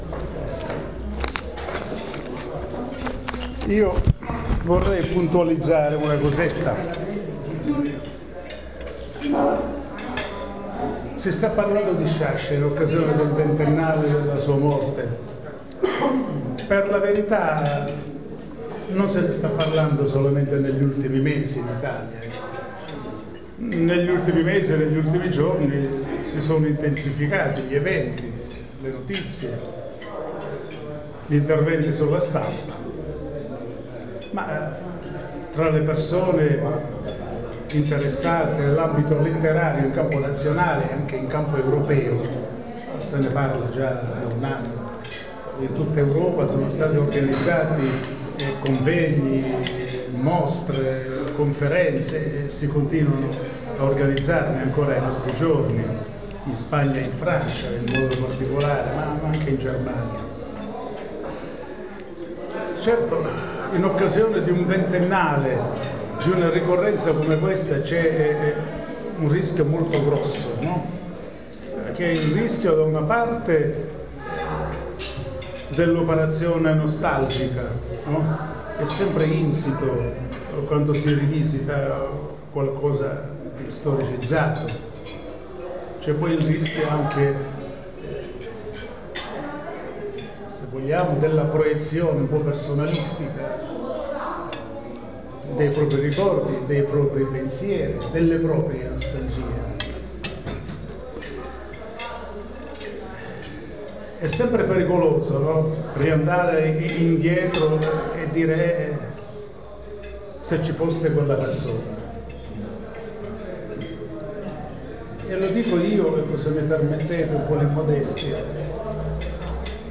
all'incontro del 25 novembre 2009 di Avola in laboratorio